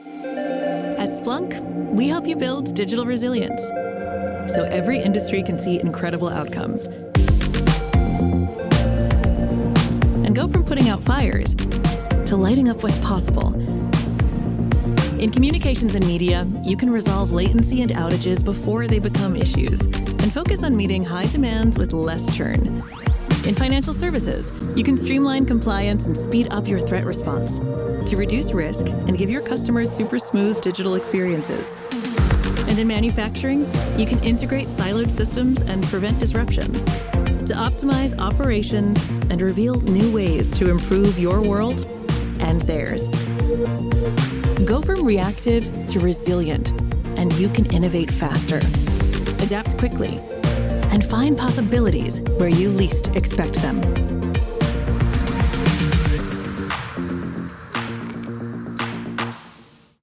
Inglés (Americano)
Natural, Accesible, Maduro, Amable, Cálida
Corporativo
▸ Her voice is natural, real, and emotionally present.